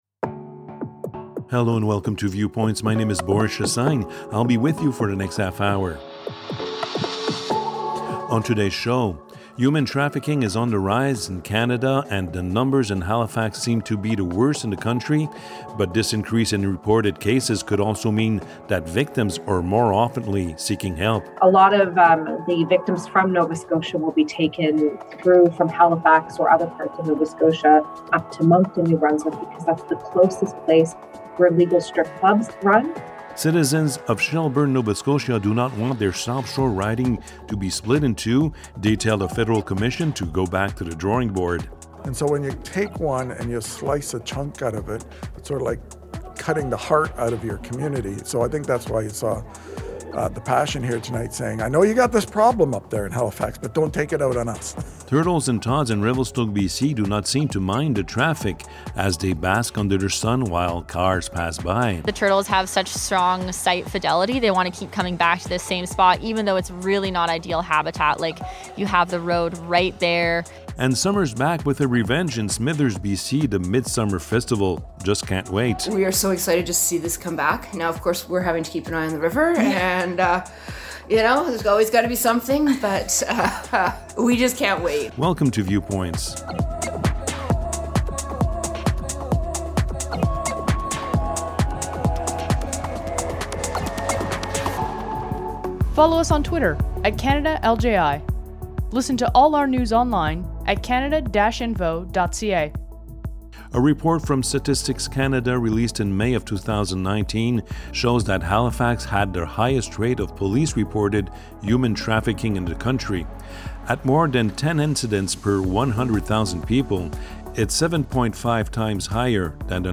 Viewpoints, season 1 episode 13 Viewpoints is a half-hour magazine aired on 30 radio stations across Canada.